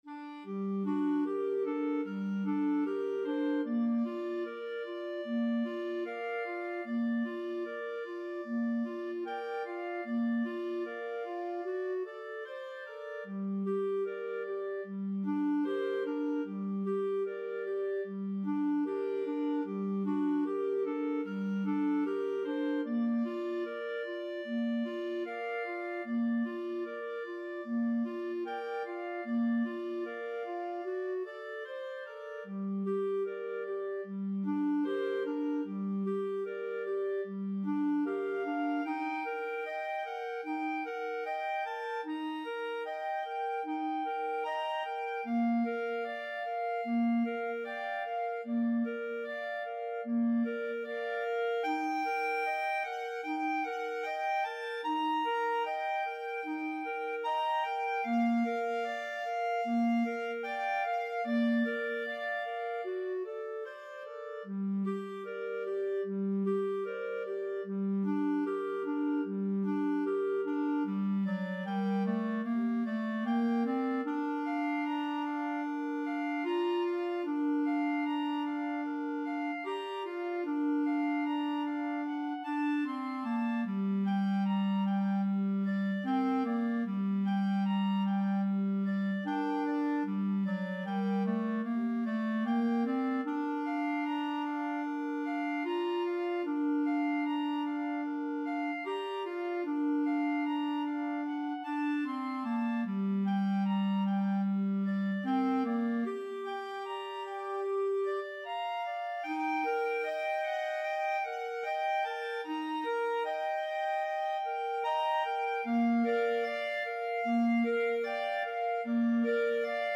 Clarinet 1Clarinet 2Clarinet 3
Molto espressivo = 150
4/4 (View more 4/4 Music)
World (View more World Clarinet Trio Music)